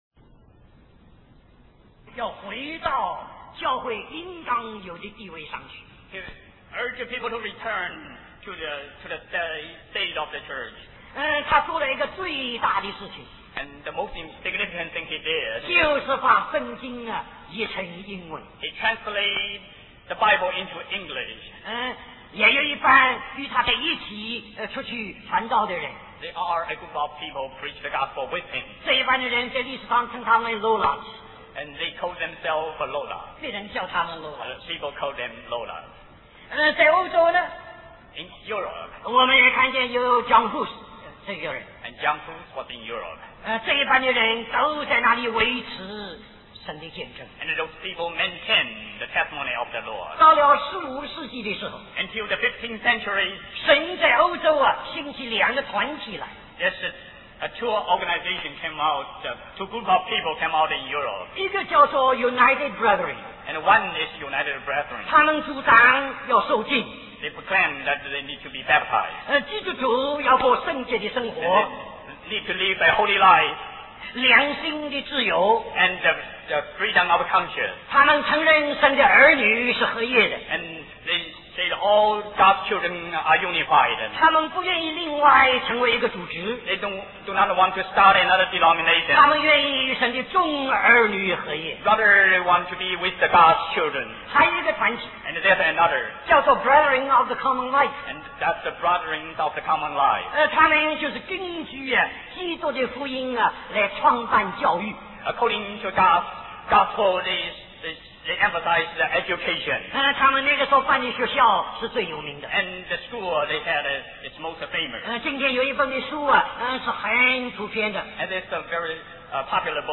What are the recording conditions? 1989 Special Conference For Service, Hong Kong Stream or download mp3 Summary This message is a continuation of the message found here .